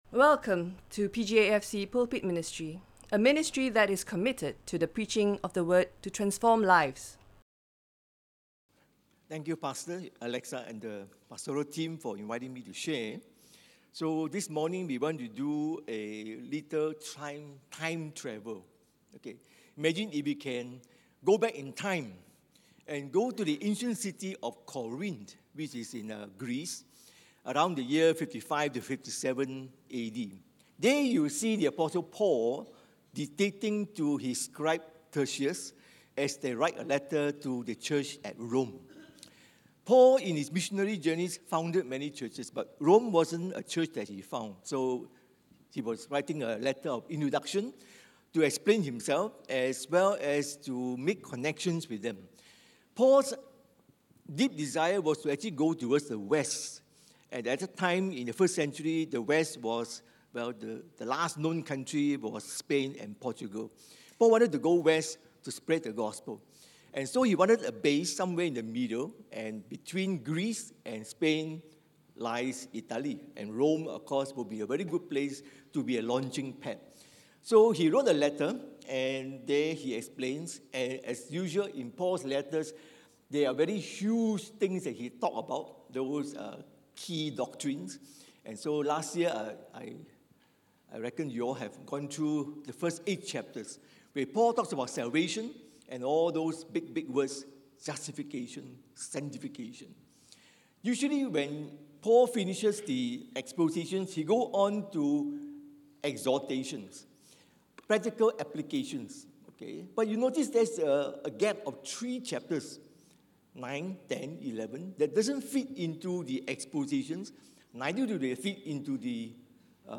Listen to Sermon Only